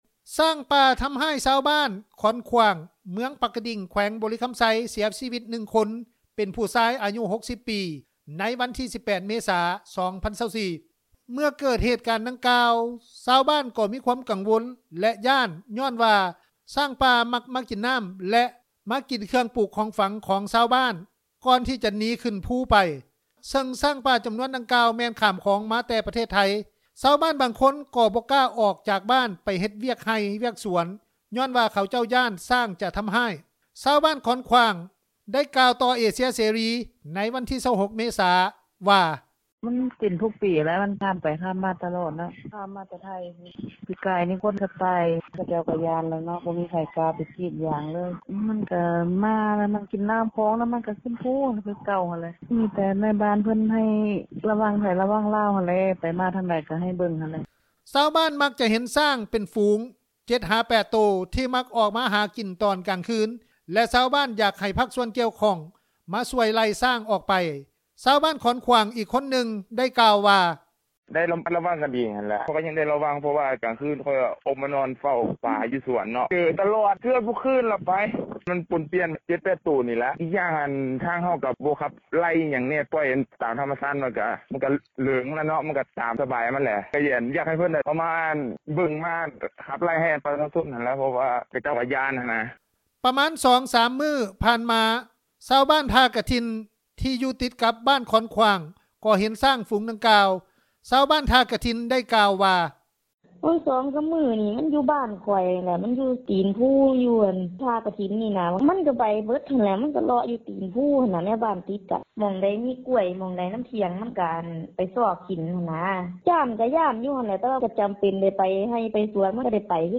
ຊາວບ້ານຂອນຂວ້າງ ໄດ້ກ່າວຕໍ່ເອເຊັຽເສຣີ ໃນວັນທີ 26 ເມສາ ວ່າ:
ຊາວບ້ານຂອນຂວ້າງອີກຄົນໜຶ່ງ ໄດ້ກ່າວວ່າ: